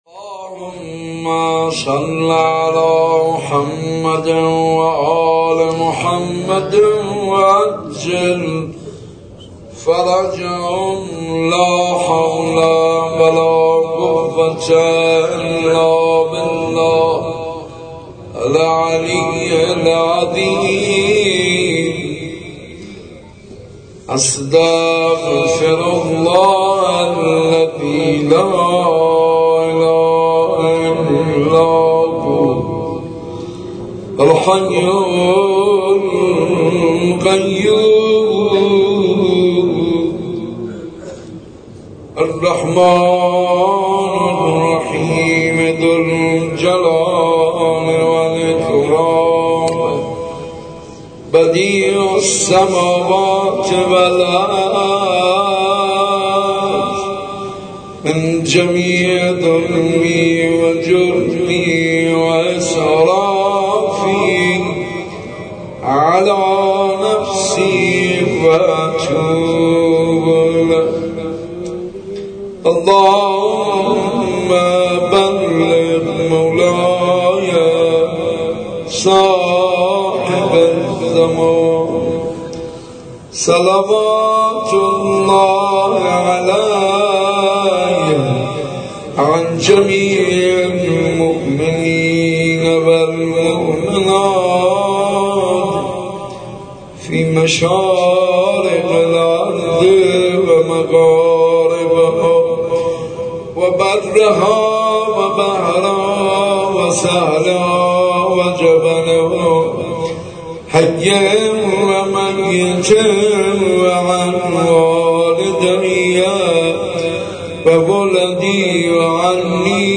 صوت/ زیارت عاشوای حاج منصور ارضی/1 بهمن کد خبر : ۲۰۲۸۶ عقیق: مراسم قرائت زیارت عاشورا صبح امروز در حسینیه صنف لباس فروشان تهران با مداحی حاج منصور ارضی برگزار شد.